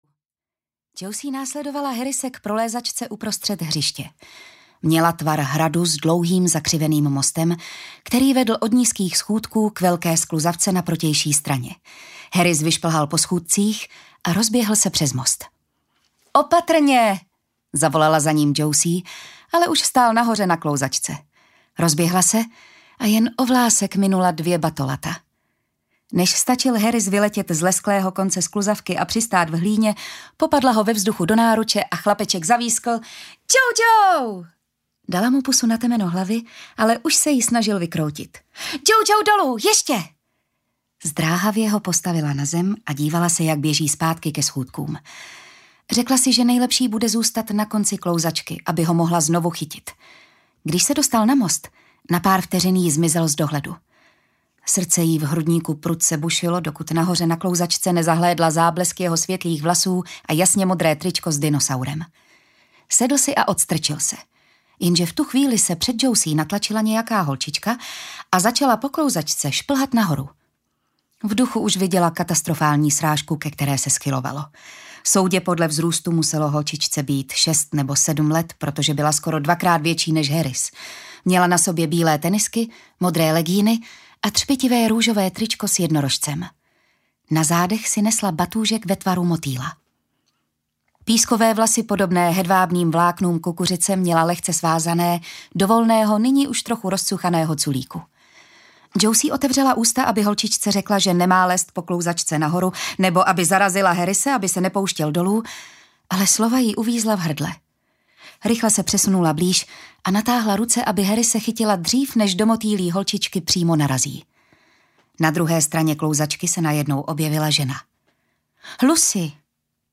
Tichý pláč audiokniha
Ukázka z knihy